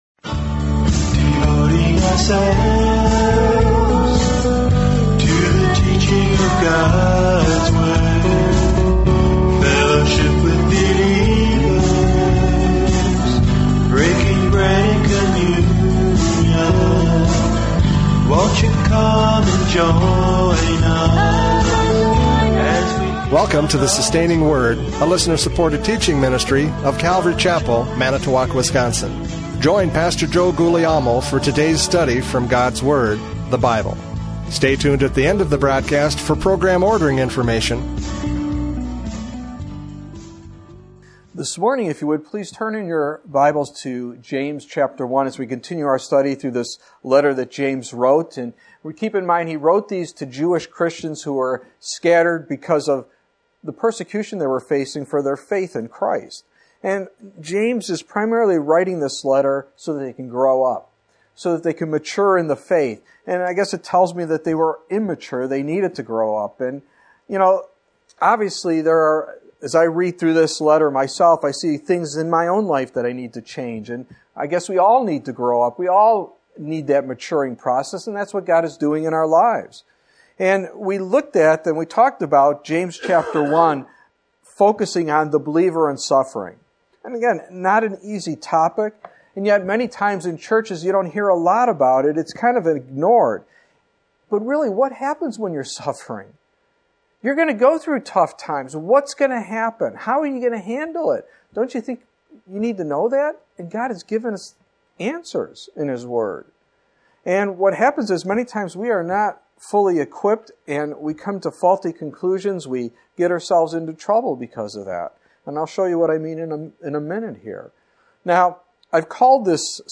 Preacher
James 1:13-18 Service Type: Radio Programs « James 1:9-12 Encouragement in Trials!